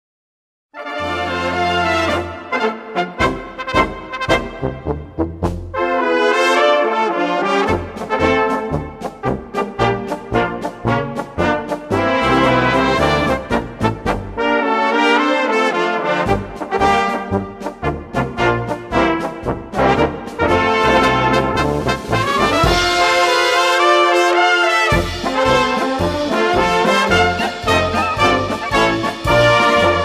Gattung: Marschpolka für Blasorchester
Besetzung: Blasorchester
Polka für sinfonisches Blasorchester